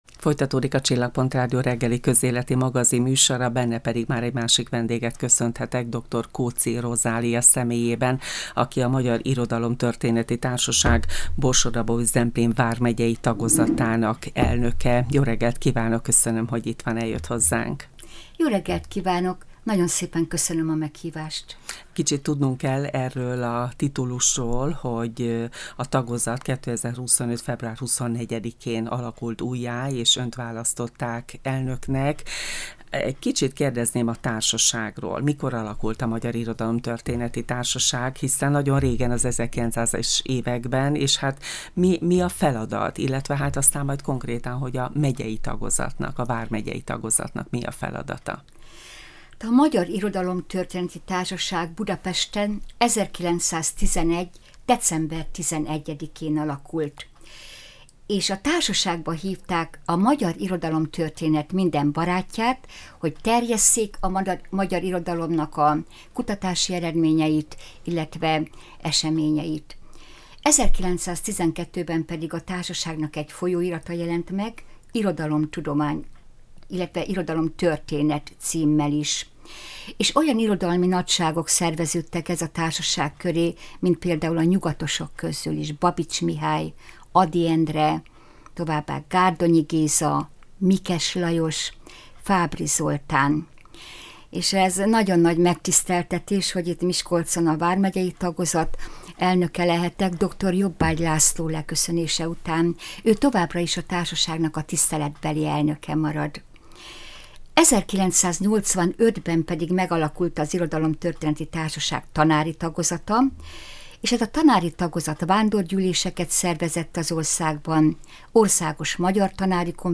beszélgettünk a Csillagpont Rádió közéleti magazinműsorában.